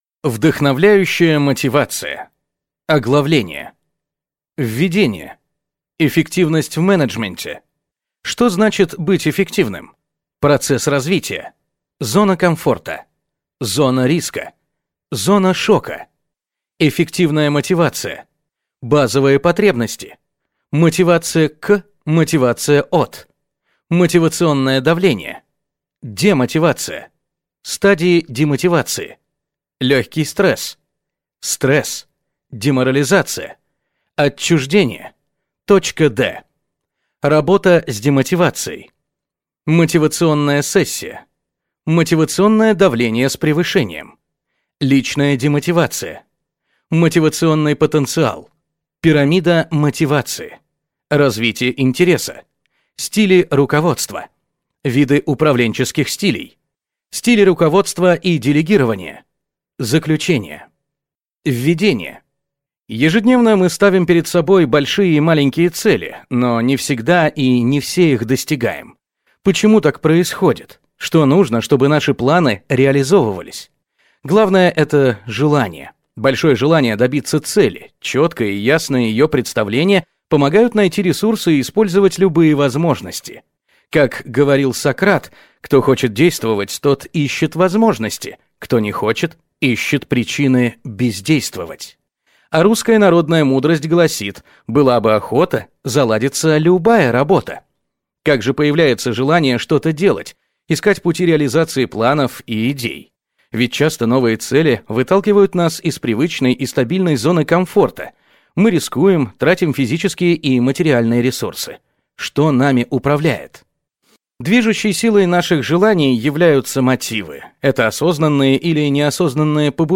Аудиокнига Вдохновляющая мотивация | Библиотека аудиокниг